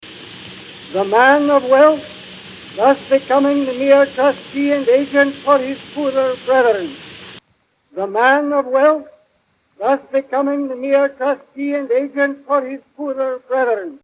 The second clip is a recording of Andrew Carnegie from 1914.
In this instance, I used RX2 to reduce the background noise with Denoiser and enhanced Carnegie’s vocals with the Equalizer.